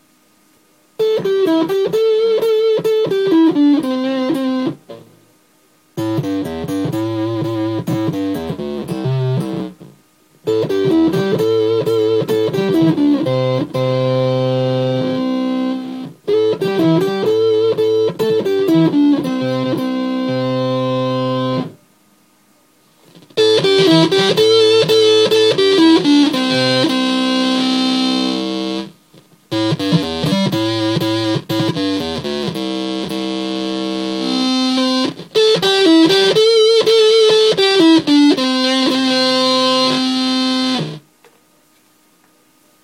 Blue Box klón, választható módosításokkal:
octave select: kapcsolóval választhatunk, hogy egy-, vagy két oktávval mélyebb hangot szeretnénk
filter: kapcsolóval választhatunk, hogy négyszög (zorzított) vagy háromszög ("sima") hullámformájú legyen a hang